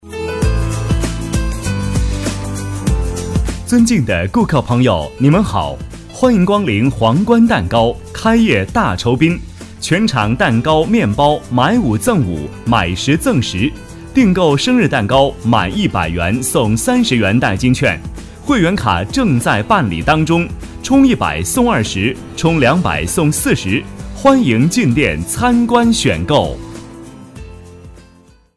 C男65号
【促销】大气亲和 店堂广告 轻快音乐
【促销】大气亲和 店堂广告 轻快音乐.mp3